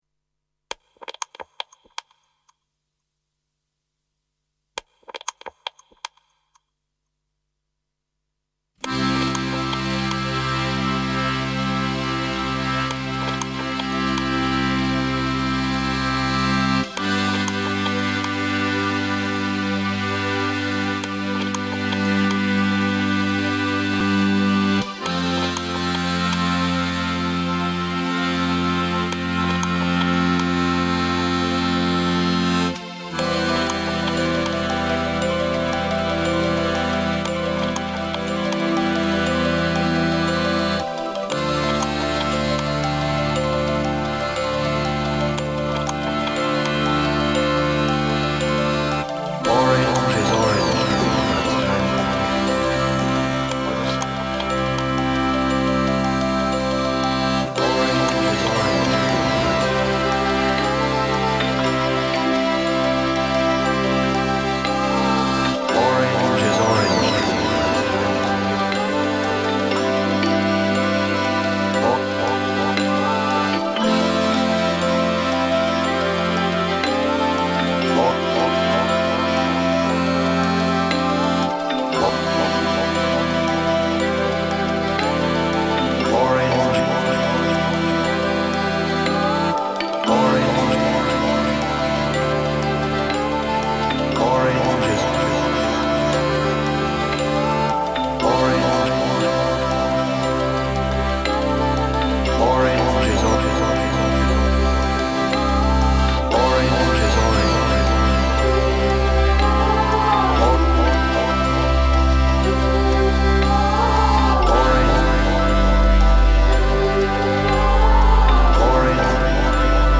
including a load of 91-93 progressive house